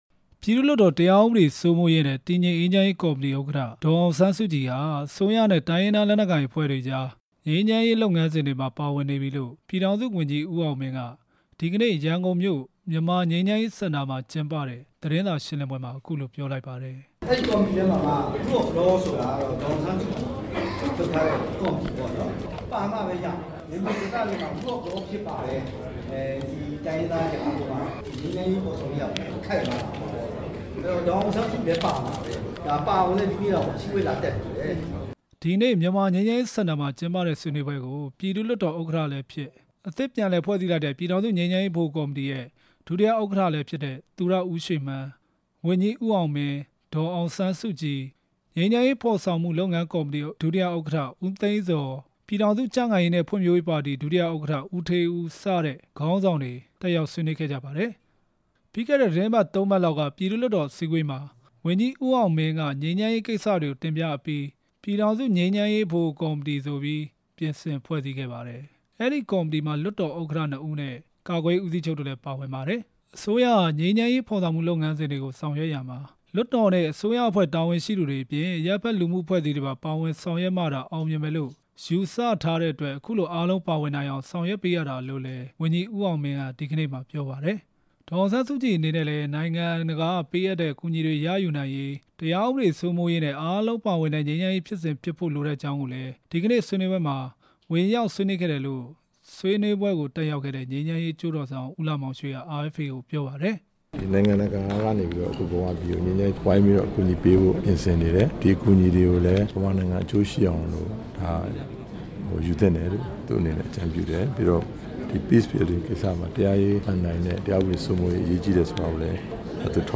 ရန်ကုန်မြို့က မြန်မာ့ငြိမ်းချမ်းရေးစင်တာမှာကျင်းပတဲ့ သတင်းစာရှင်းလင်းပွဲမှာ ဝန်ကြီး ဦးအောင်မင်းက အခုလို ပြောသွားတာပါ။